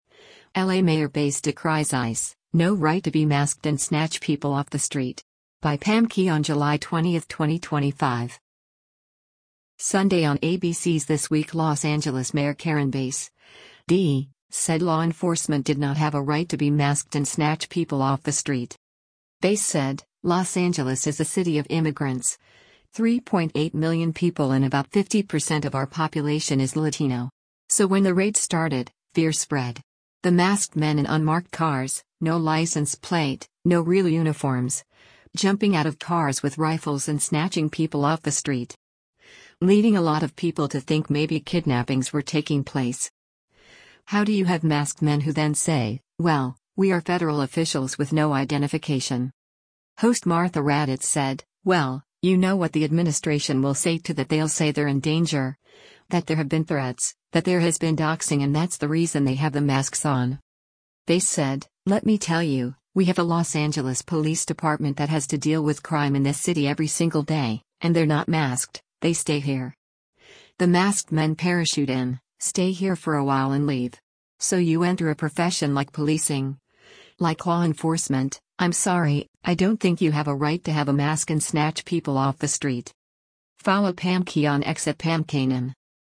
Sunday on ABC’s “This Week” Los Angeles Mayor Karen Bass (D) said law enforcement did not have a right to be masked and “snatch people off the street.”